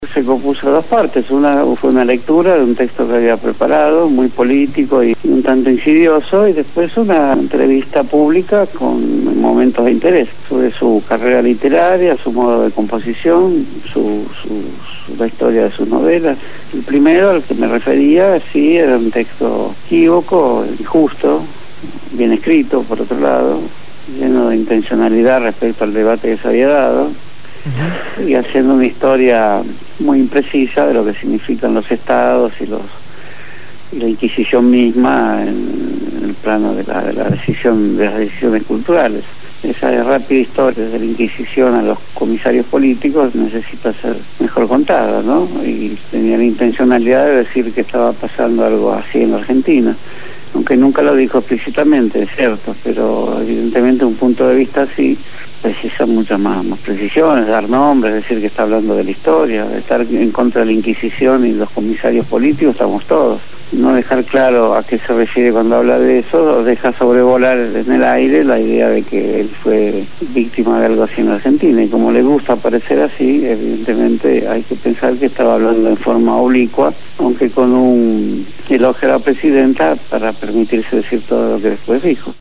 en una entrevista